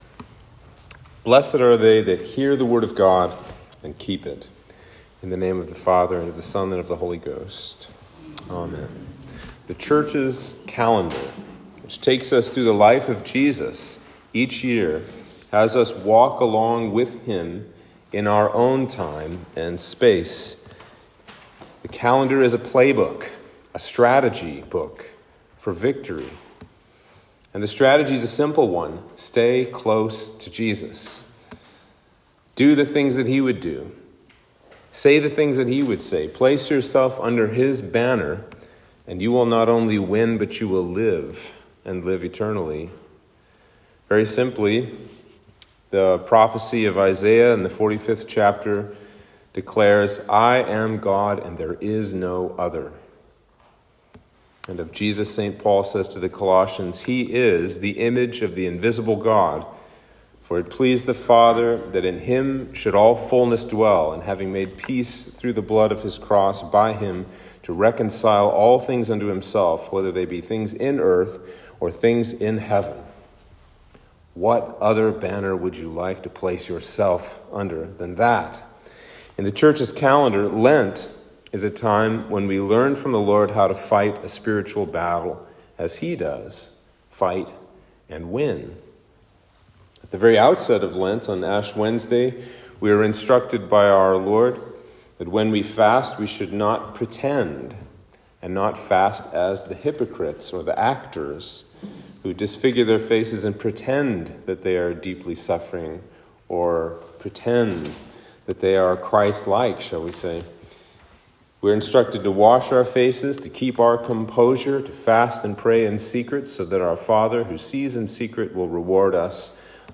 Sermon for Lent 3